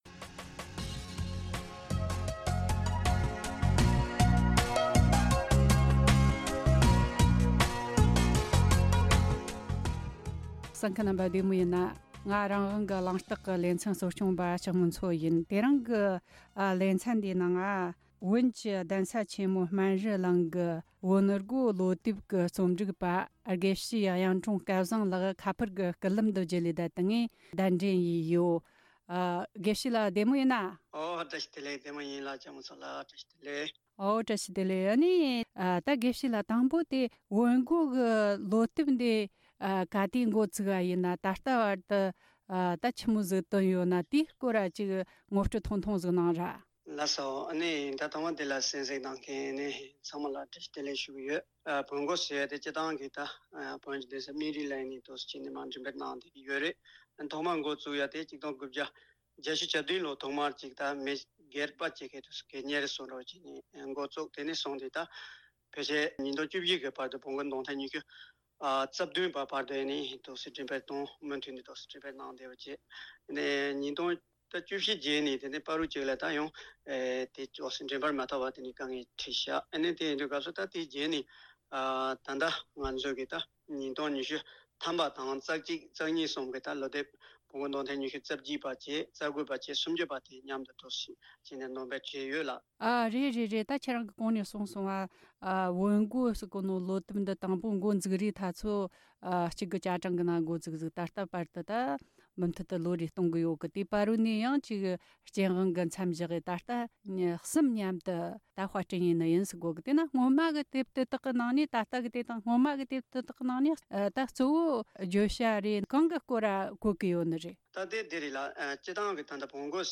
གླེང་མོལ་བྱས་པར་གསན་རོགས་གནོངས།